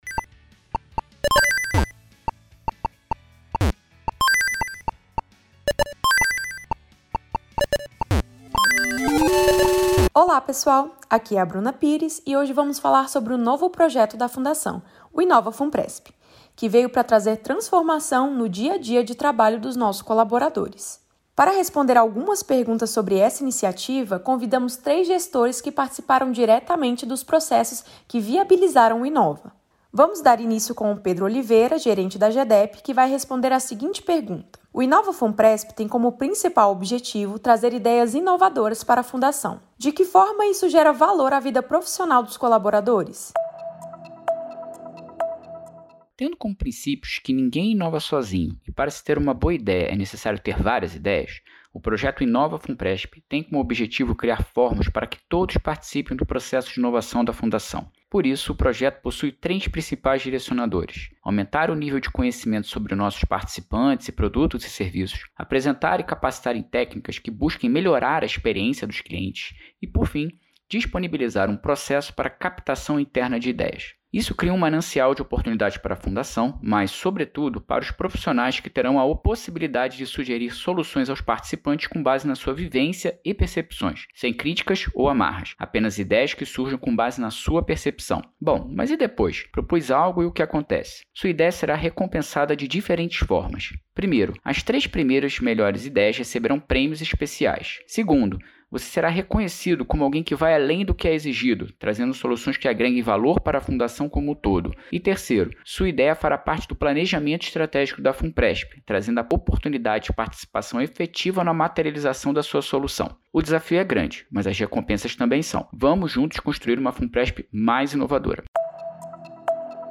Os gestores que criaram o Inova Funpresp contam tudo nesse podcast especial sobre esse projeto incrível!